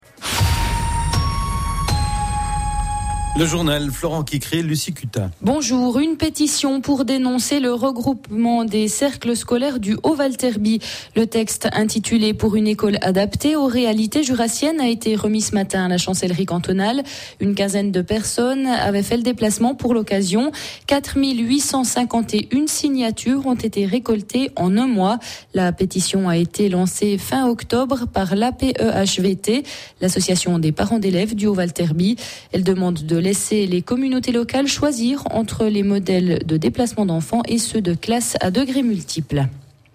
�couter l’extrait du journal de midi, le 21 novembre 2008